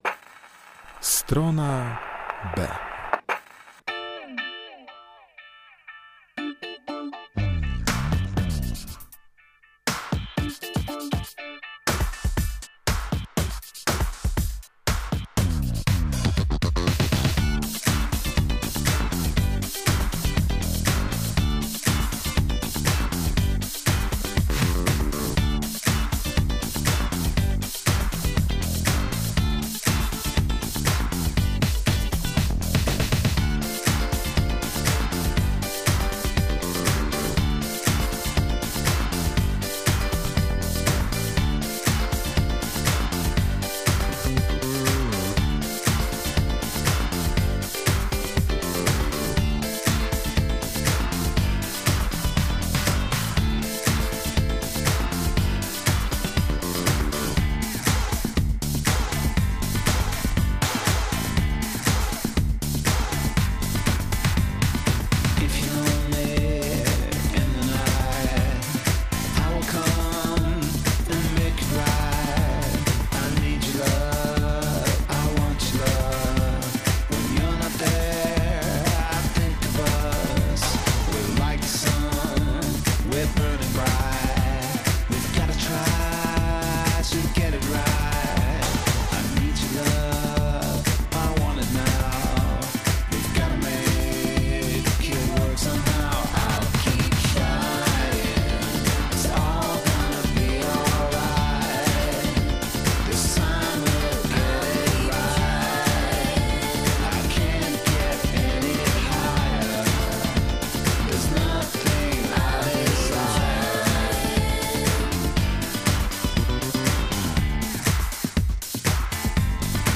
W Stronie B gramy elektronicznie!